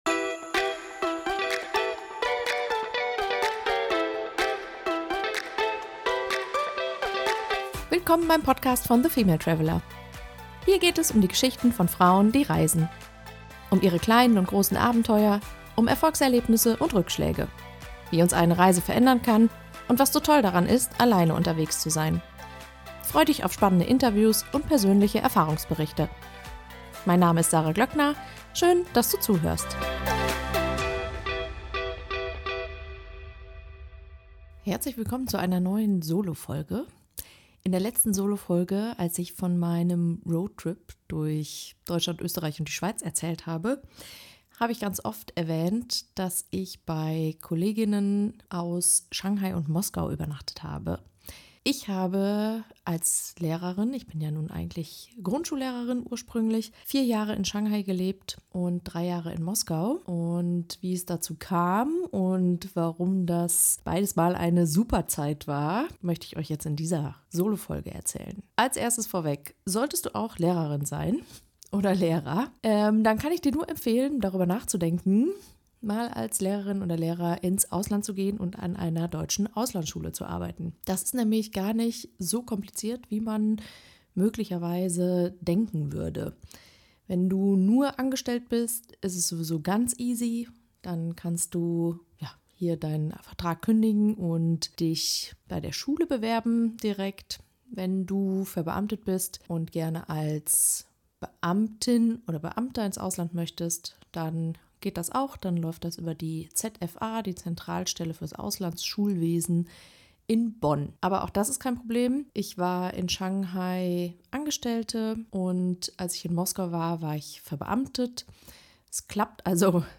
Beschreibung vor 11 Monaten In dieser Solofolge spreche ich darüber, wie es ist, als Lehrerin im Ausland zu arbeiten.